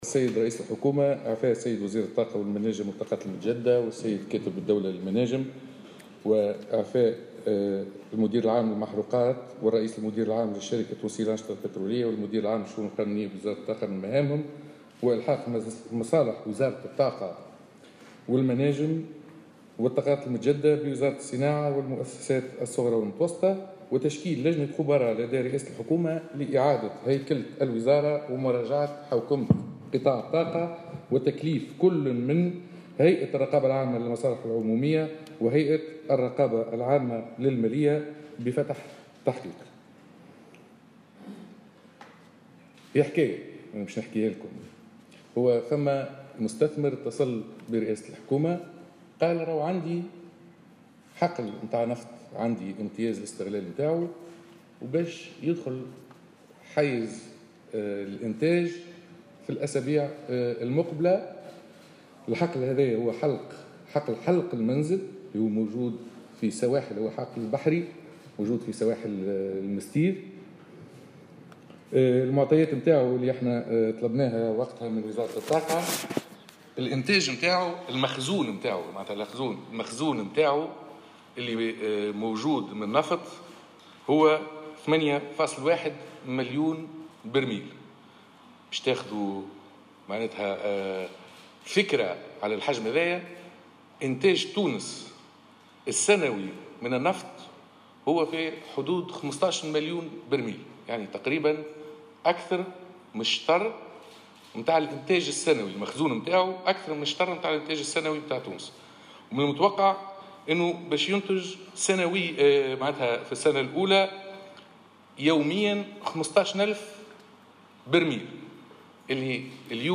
الناطق باسم الحكومة يوضّح أسباب الإقالات في وزارة الطاقة